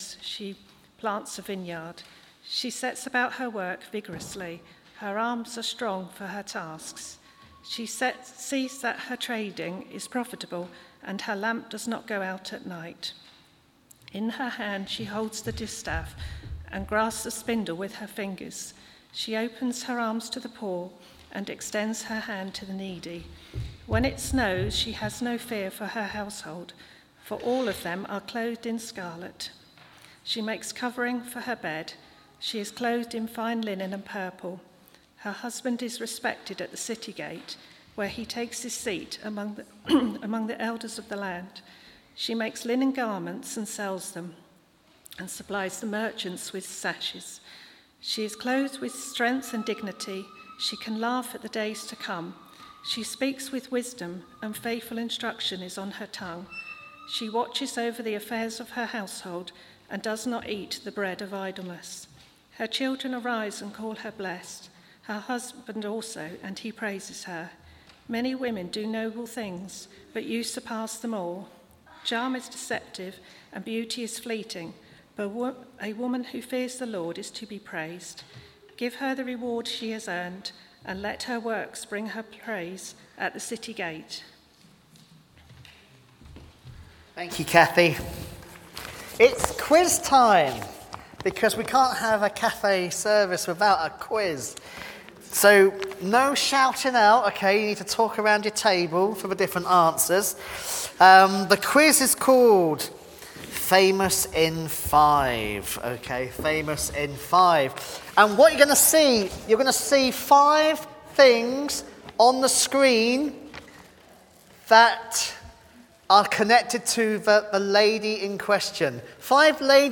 Eastgate Union Louth - Mothering Sunday talk